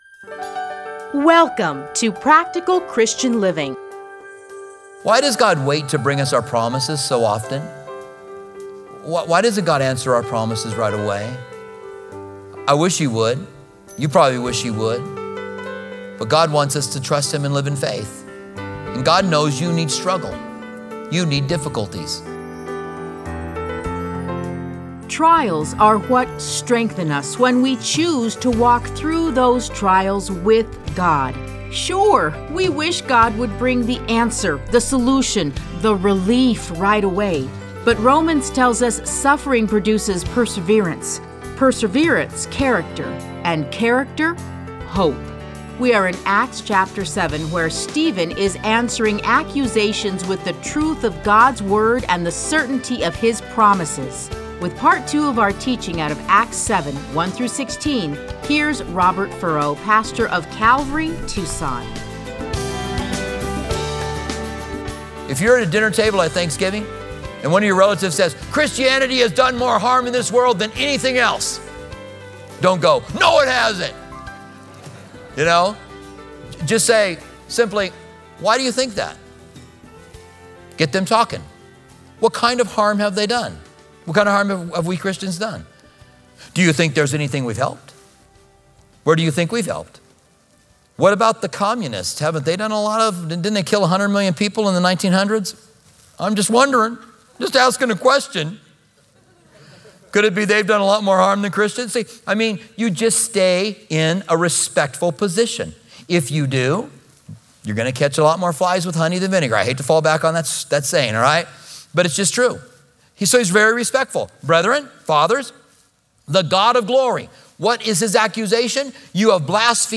Listen to a teaching from Acts 7:1-16.